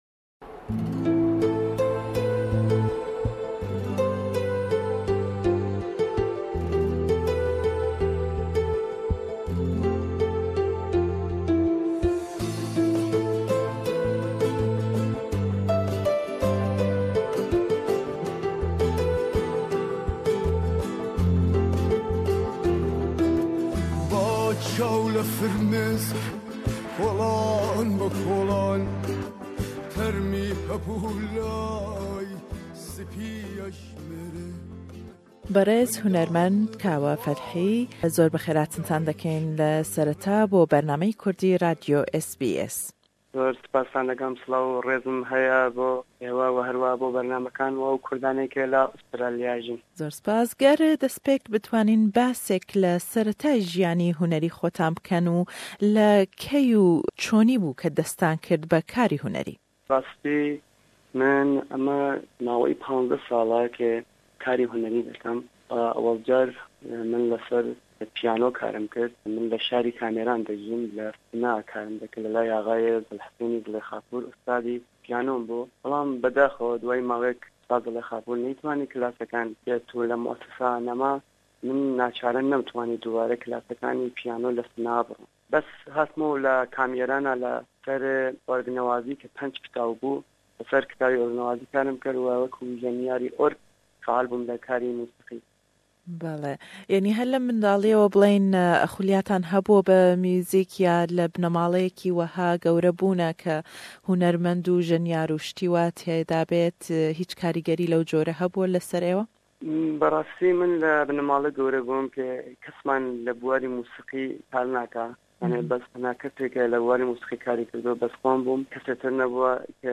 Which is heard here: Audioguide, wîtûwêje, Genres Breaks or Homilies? wîtûwêje